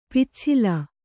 ♪ picchila